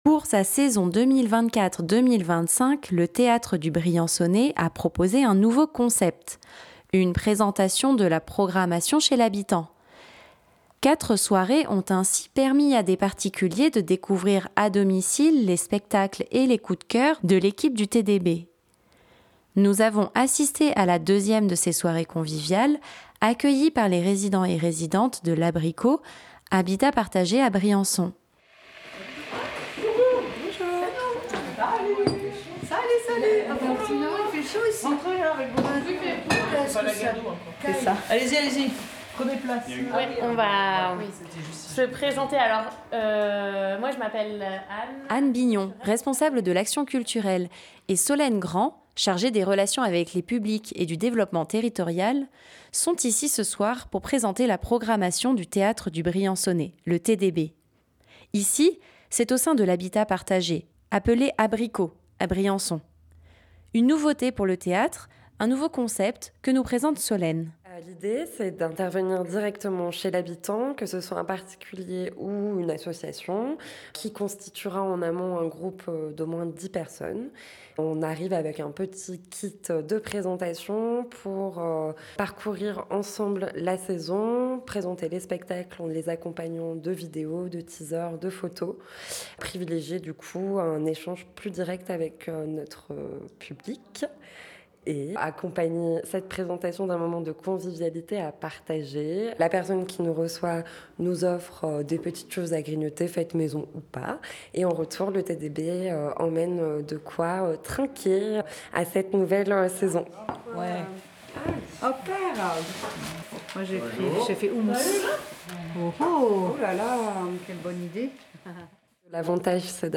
Nous avons assisté à la deuxième de ces soirées, accueillie par les résident.e.s de l'Habrico, habitat partagé à Briançon.